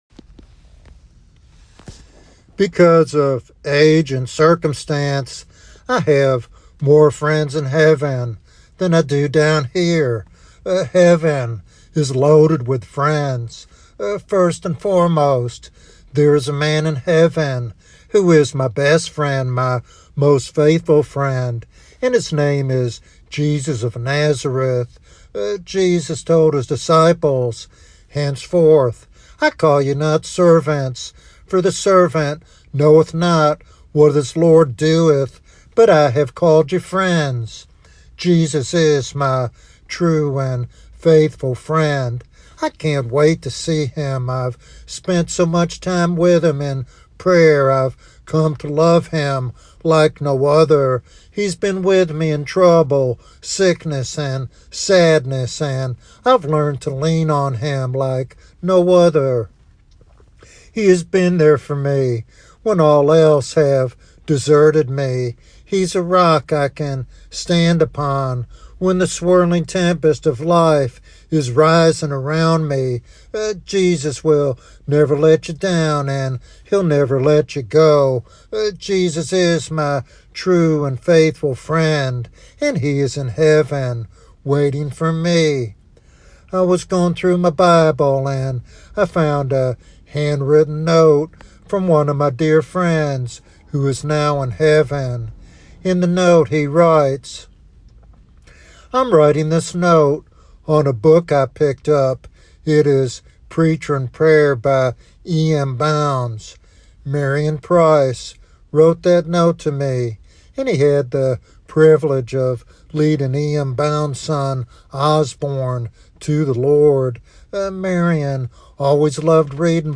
This devotional sermon offers hope and reassurance about the afterlife and the enduring bonds of Christian fellowship.
Sermon Outline